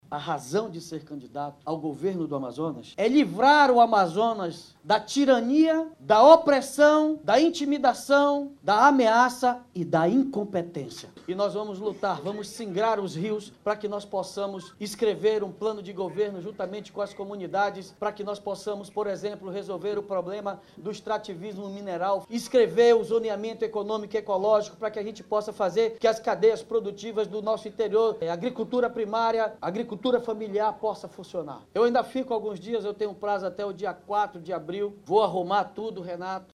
O anúncio foi feito durante evento em Manaus. Em discurso, Almeida afirmou que pretende fortalecer a parceria entre o Governo do Estado e a Prefeitura de Manaus, caso seja eleito.